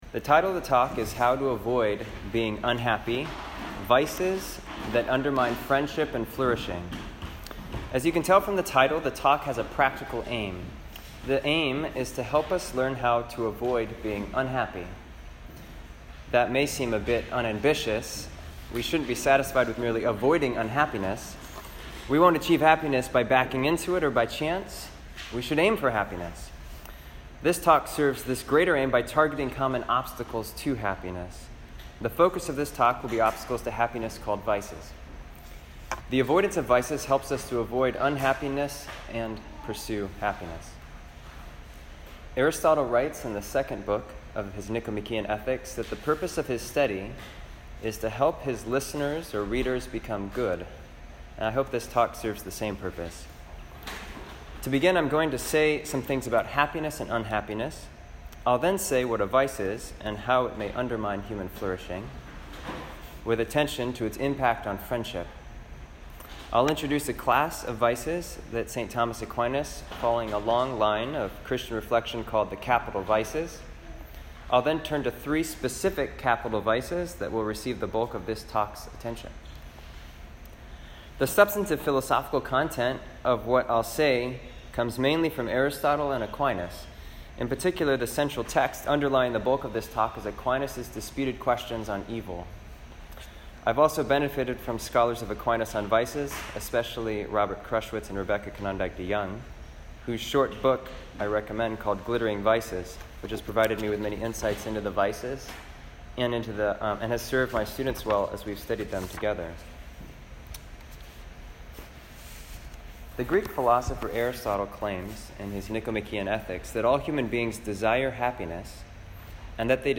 Christianity, Society & Culture, Catholic Intellectual Tradition, Catholic, Philosophy, Religion & Spirituality, Thomism, Catholicism
This lecture was given to the undergraduate chapter at the University of Virginia on 17 September 2019.